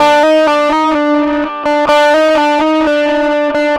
Track 10 - Guitar 06.wav